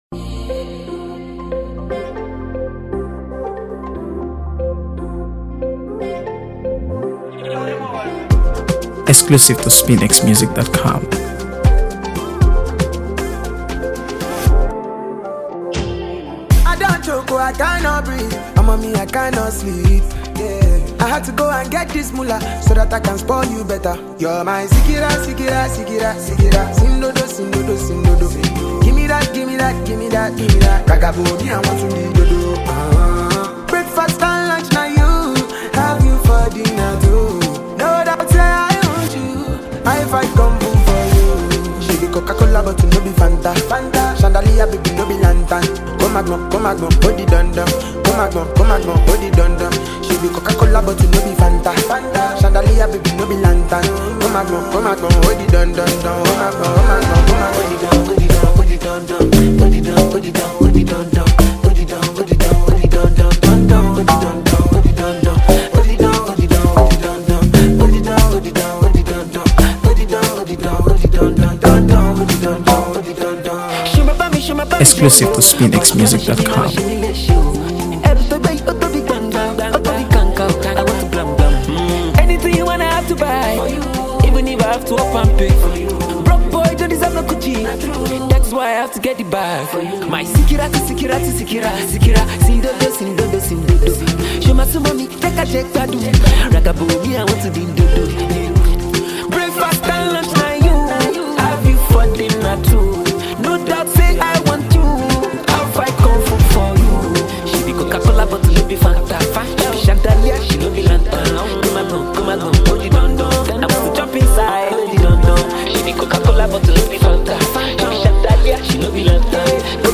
AfroBeats | AfroBeats songs
With its infectious rhythm and feel-good vibe